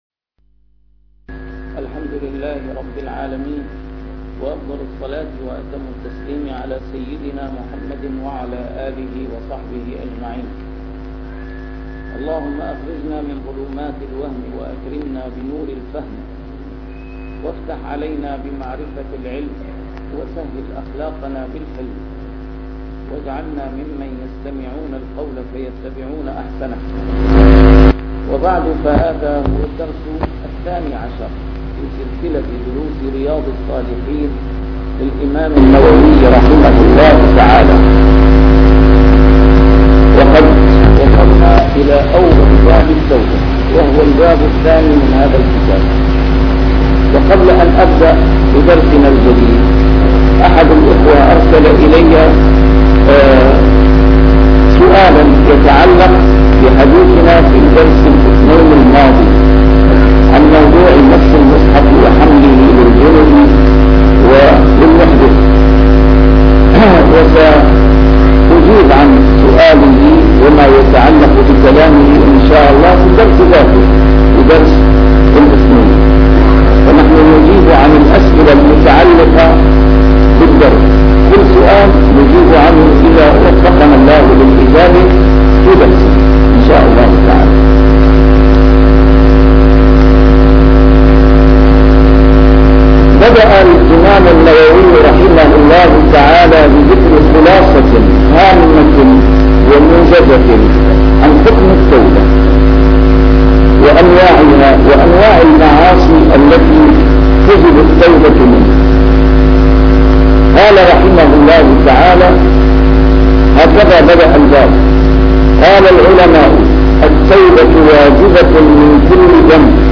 A MARTYR SCHOLAR: IMAM MUHAMMAD SAEED RAMADAN AL-BOUTI - الدروس العلمية - شرح كتاب رياض الصالحين - 12- شرح رياض الصالحين: التوبة